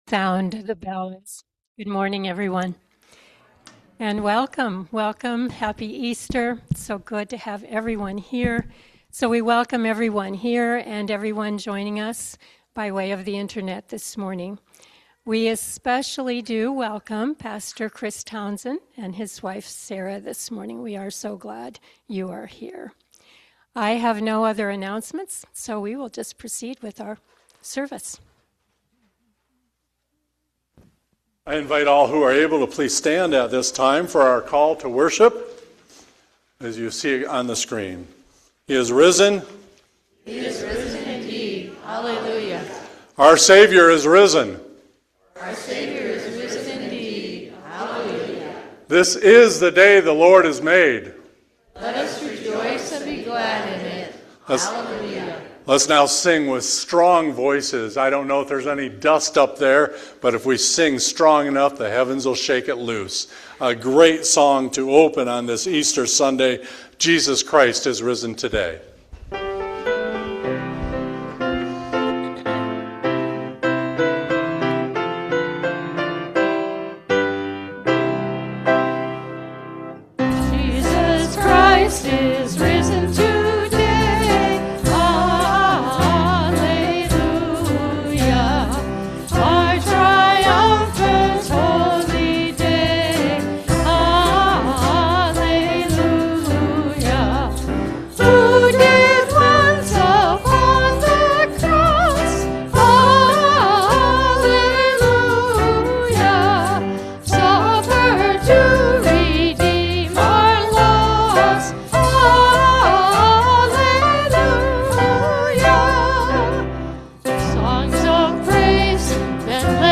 Worship-Easter-Sunday-April-5-2026-Voice-Only.mp3